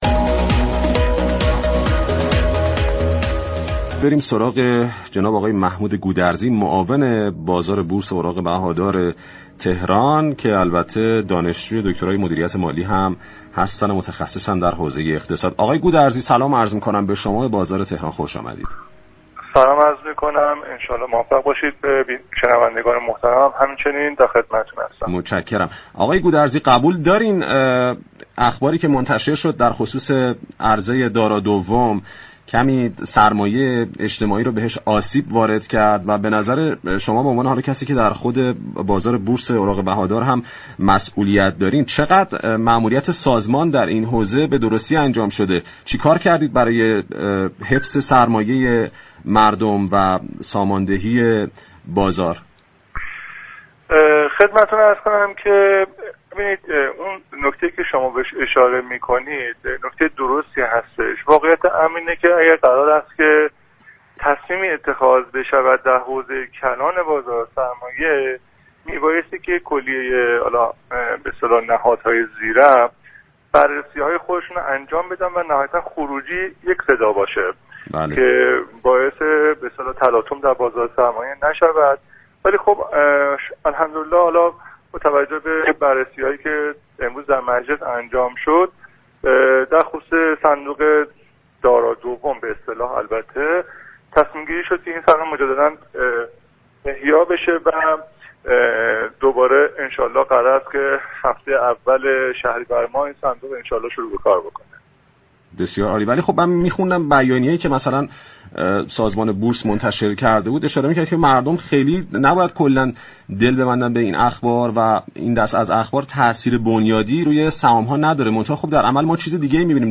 نمایندگان مجلس یازدهم در بازار تهران رادیو تهران در خصوص عرضه سهام